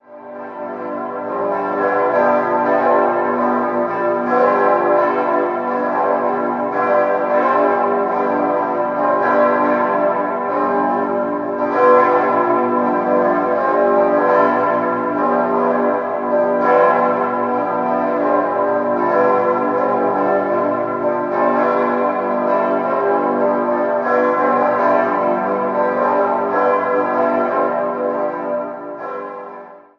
4-stimmiges Wachet-auf-Geläute: g°-h°-d'-e'
bell
Ein feierliches Geläute in sehr dezenter Lautstärke, bedingt durch die sehr hoch liegende Glockenstube und die dicht verschlossenen Schallöffnungen.